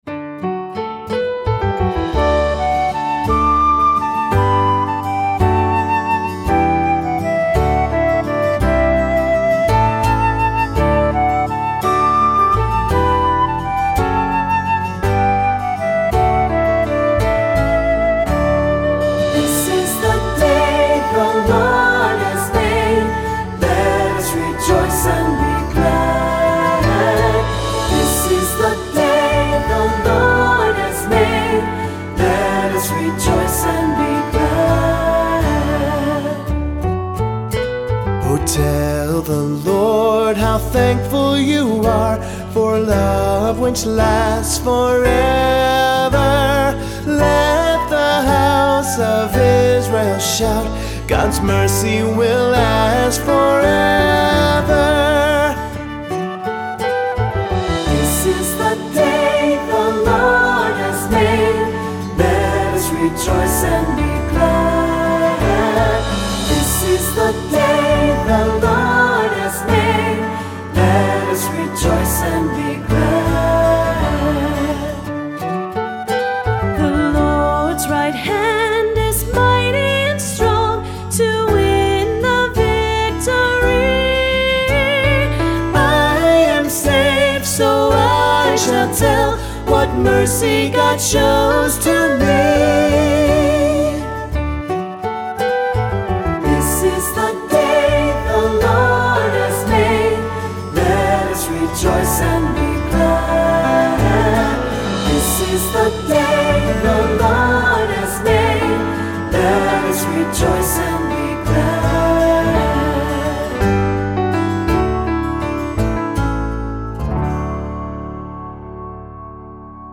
Voicing: Assembly,Cantor